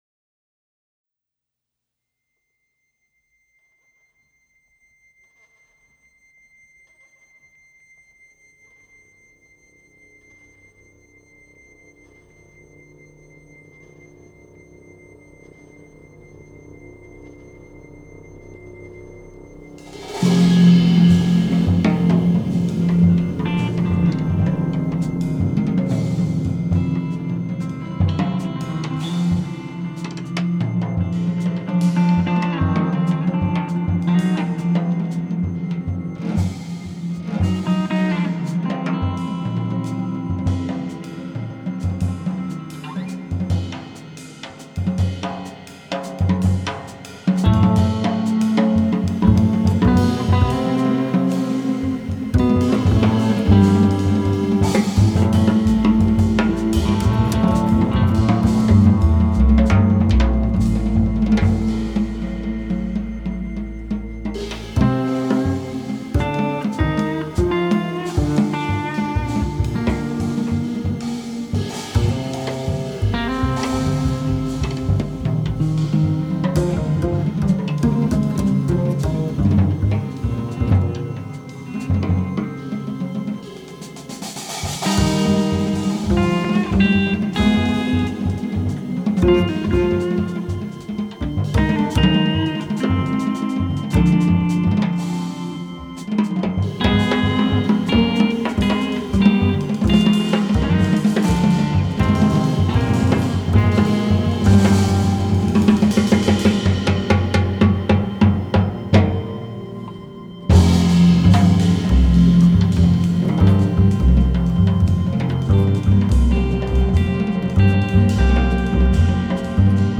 double bass
guitar
drums